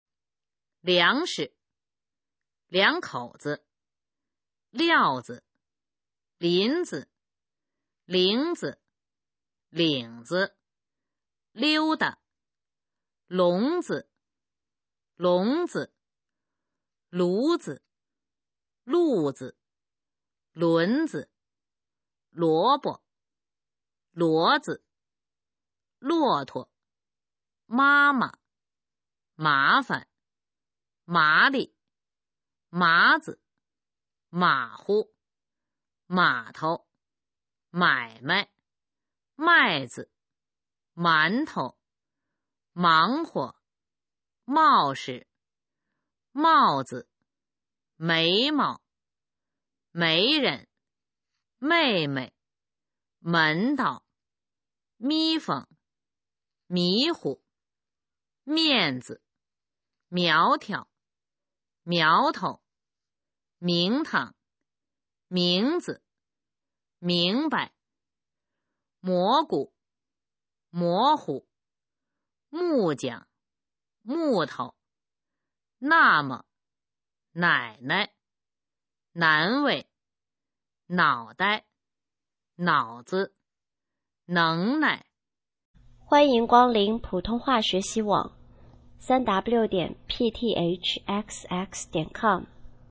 普通话水平测试用必读轻声词语表示范读音第251-300条